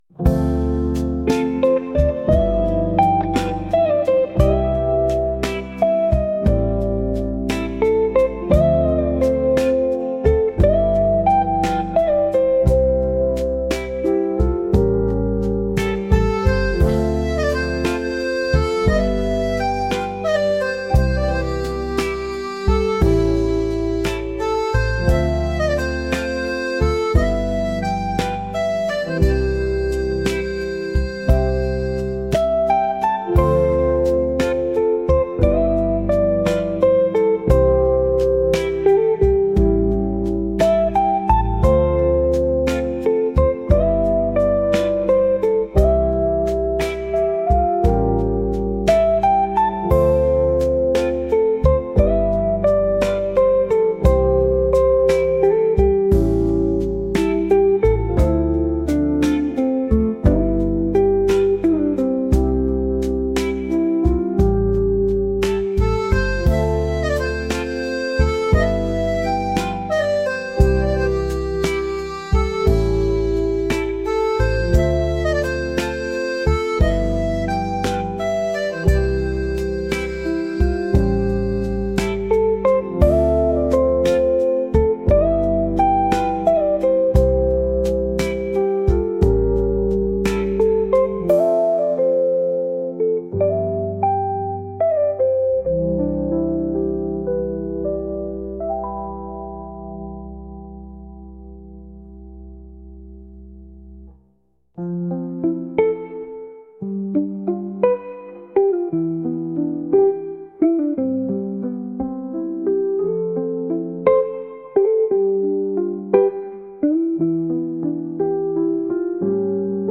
ハーモニカとピアノとエレキギターが順番に奏でるゆったり曲です。